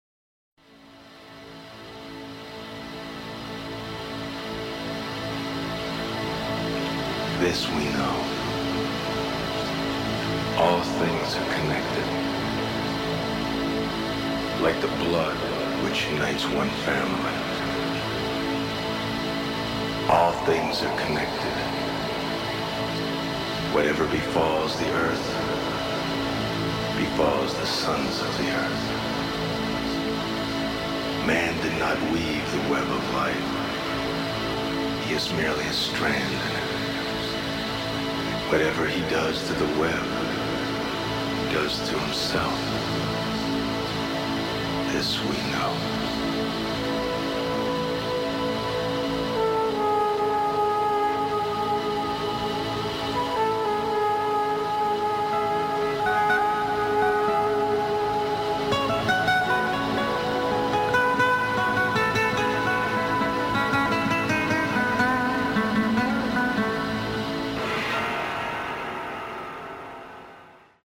This haunting opening minute